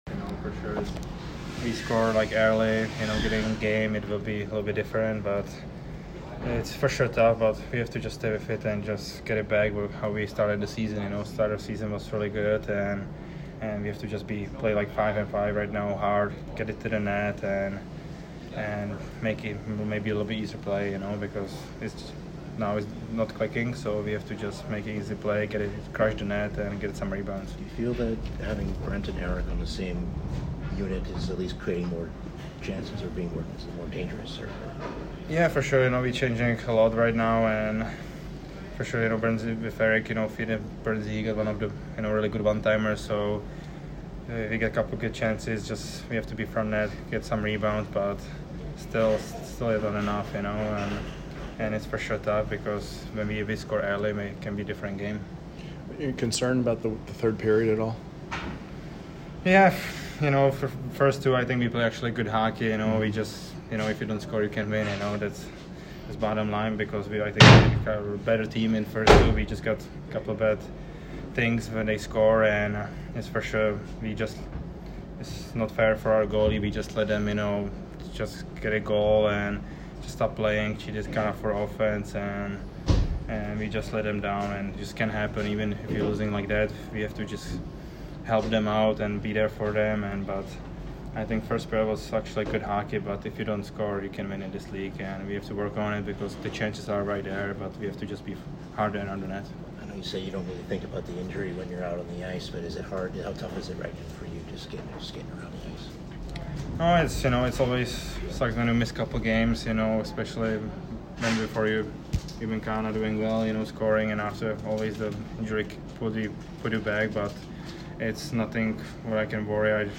Hertl post-game 12/7